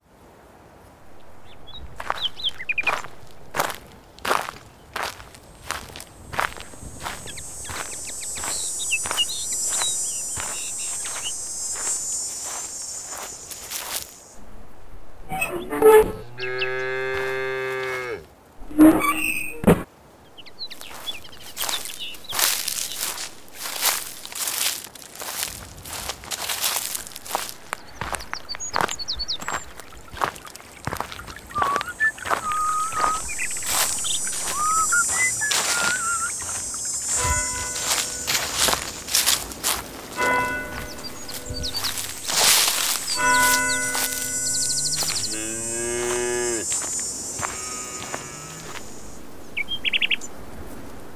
Sound design demos
Country walk